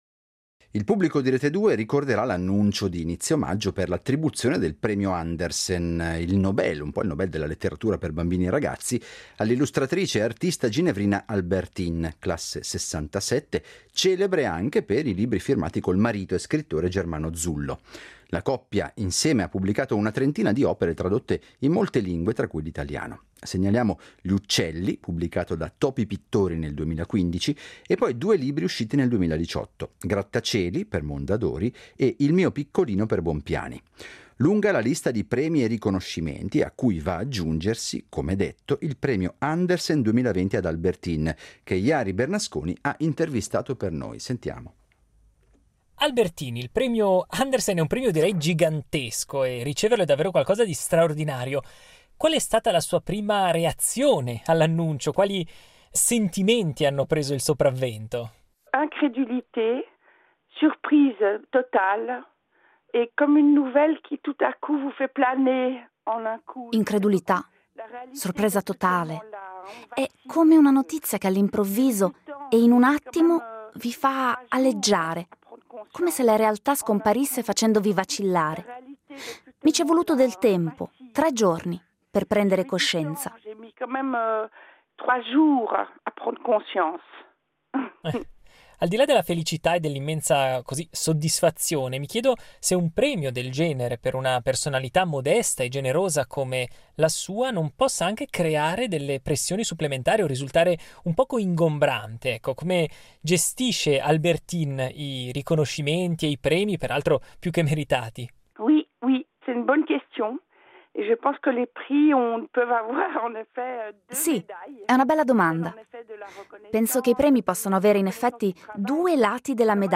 Albertine, l'illustratrice e artista ginevrina classe 1967 che si è vista attribuire all'inizio del mese il prestigioso Premio Andersen, considerato il Nobel della letteratura per bambini e ragazzi, è stata intervistata